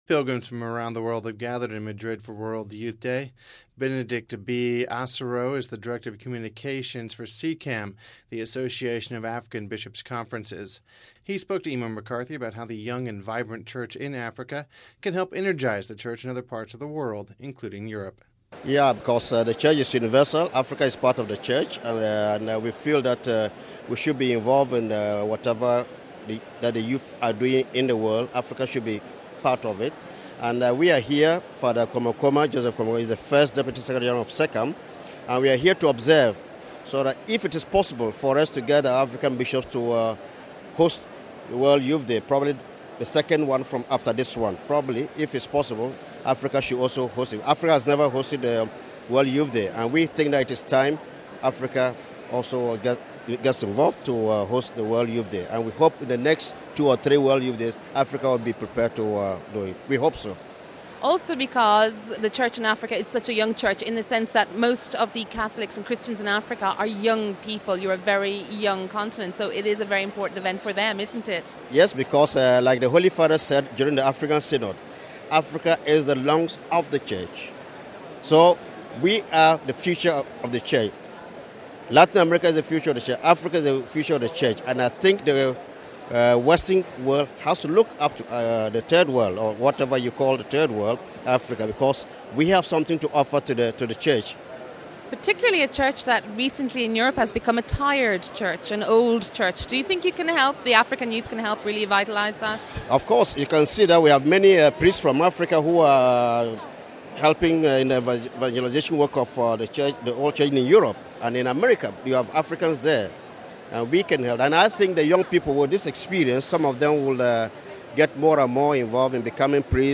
Pilgrims from around the world have gathered in Madrid for World Youth Day.